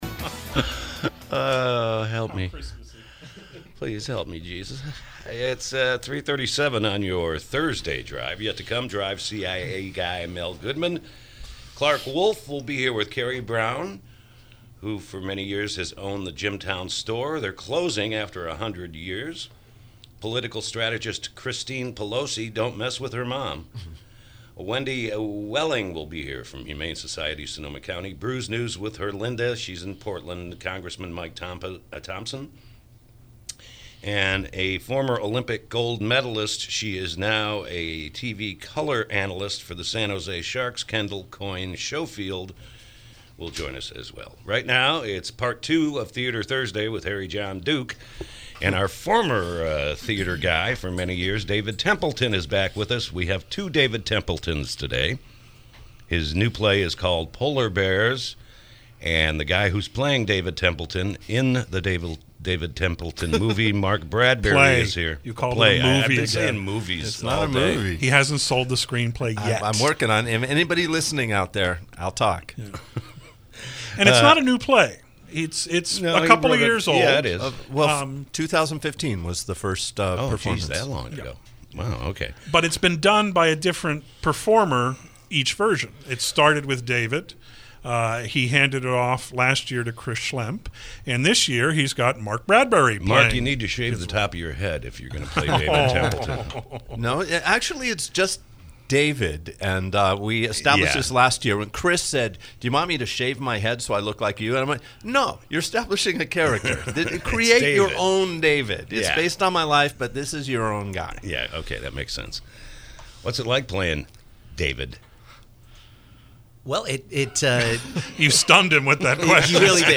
KSRO Interview: “Polar Bears”